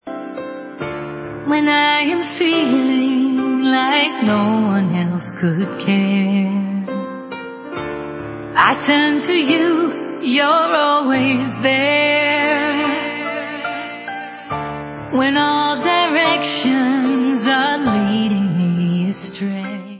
sledovat novinky v oddělení Rock